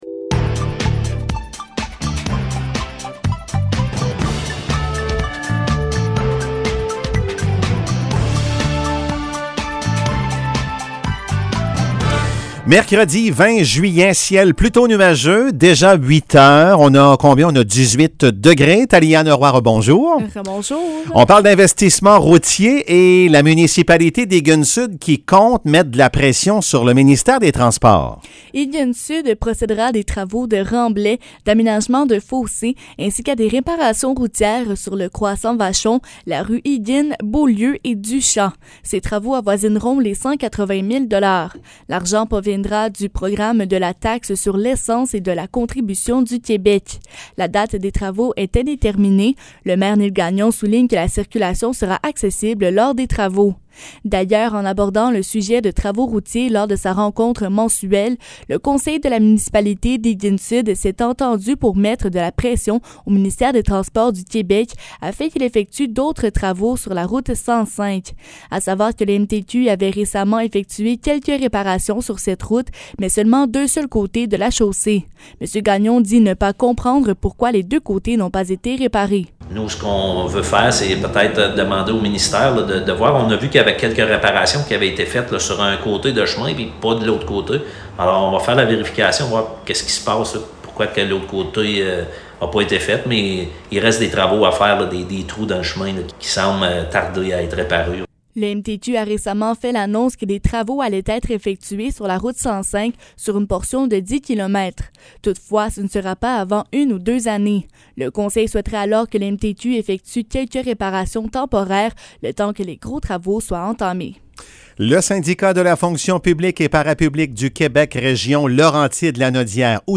Nouvelles locales - 20 juillet 2022 - 8 h